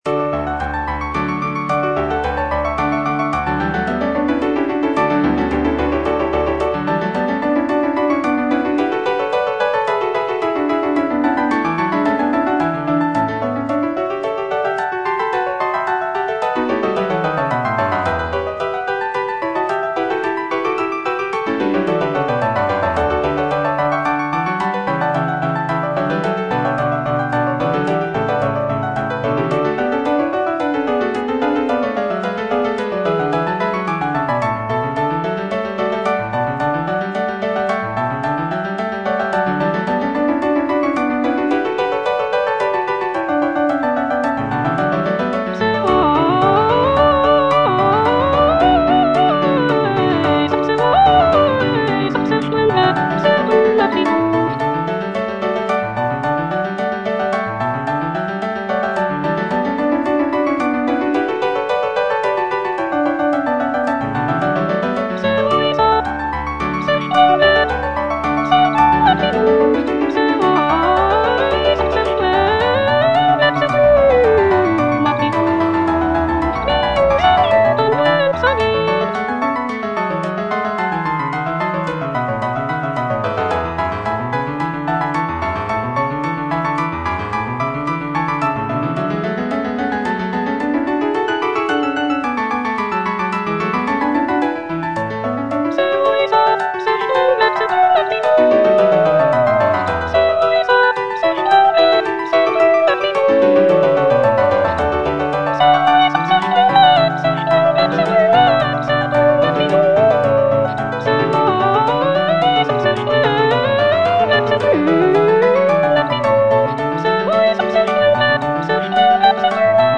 The music is lively and celebratory, with intricate counterpoint and virtuosic vocal lines.